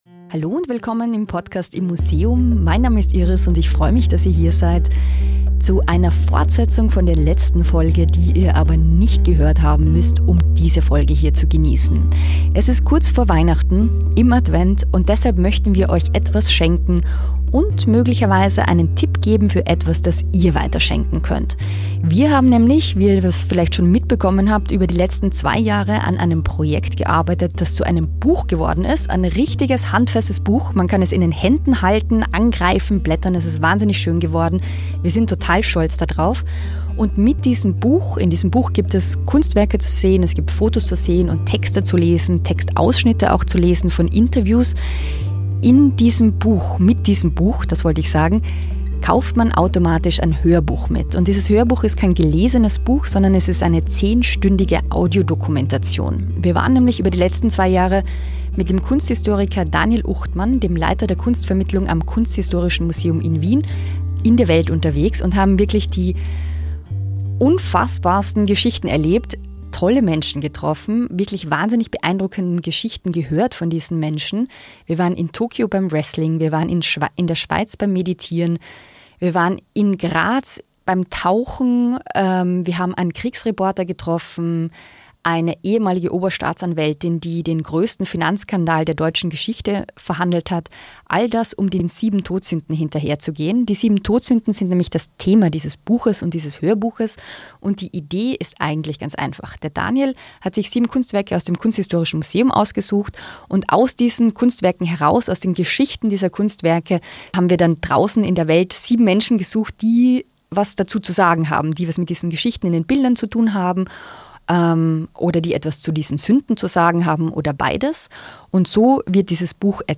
Beschreibung vor 15 Stunden Heute hört ihr einen ungekürzten Auszug aus unserer 10-stündigen Audiodokumentation zu den 7 Todsünden!